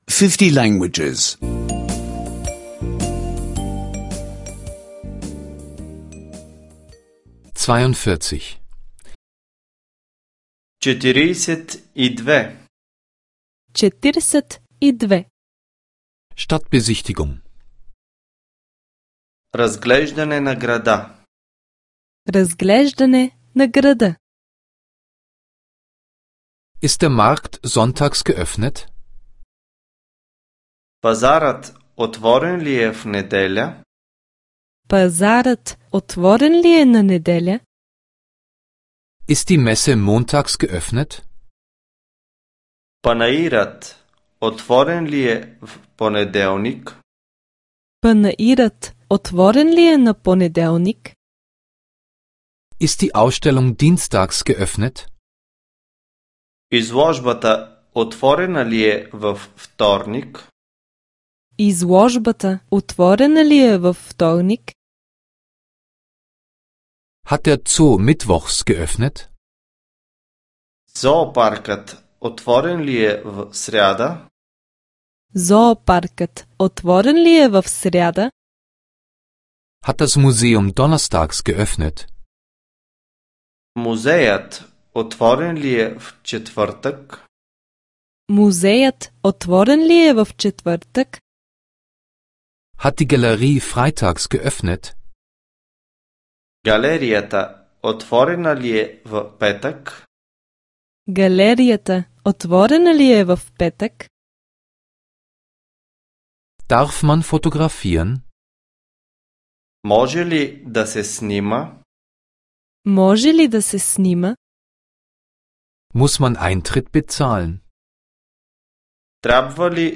Bulgarisch Audio-Lektionen, die Sie kostenlos online anhören können.